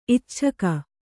♪ icchaka